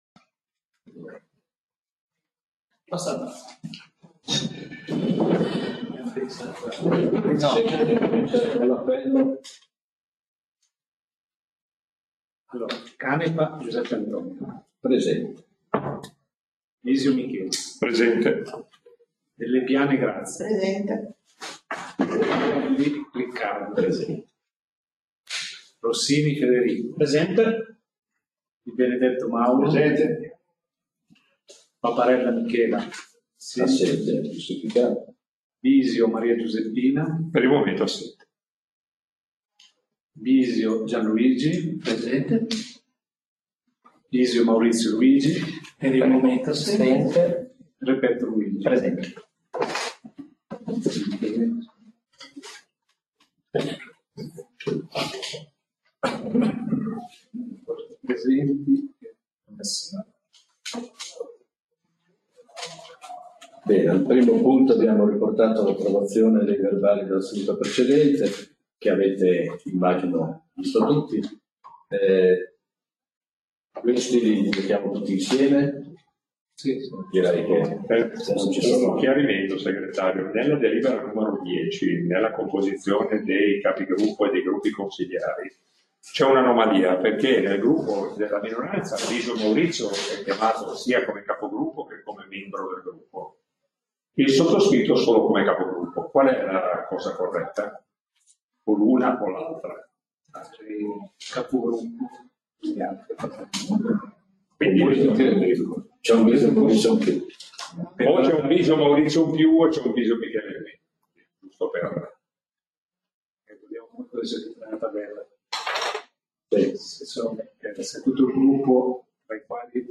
Seduta del Consiglio Comunale del 06/08/2024